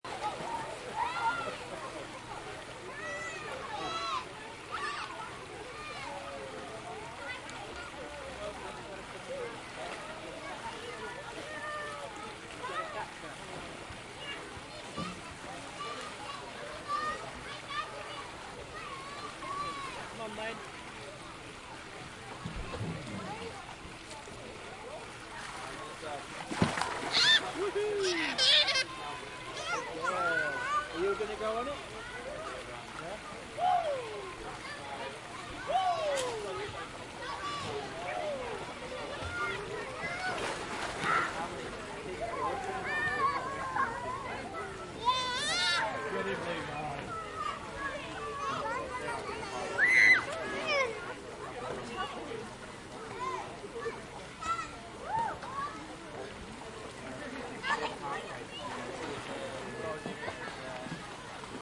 Download Swimming Pool sound effect for free.
Swimming Pool